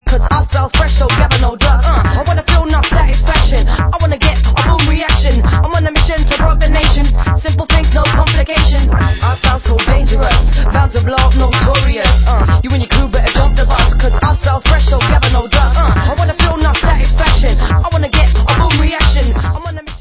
sledovat novinky v oddělení Dance/Techno